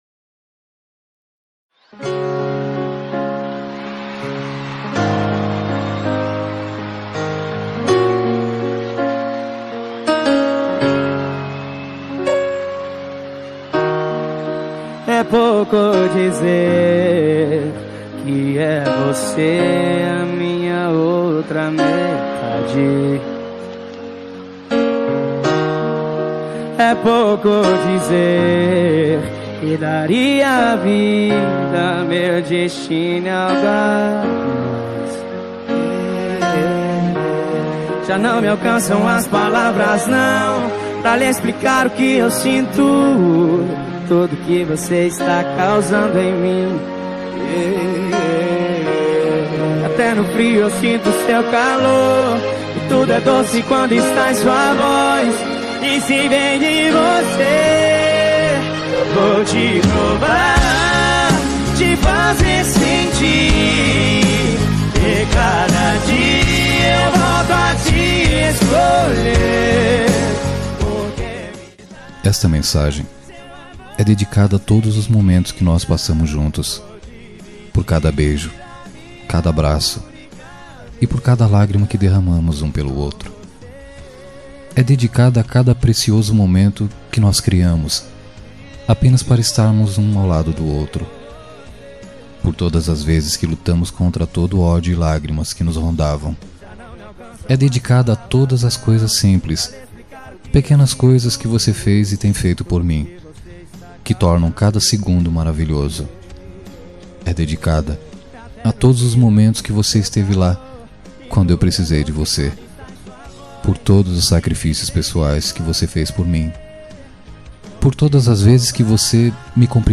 Telemensagem Romântica Lindaaa – Voz Masculina – Cód: 808484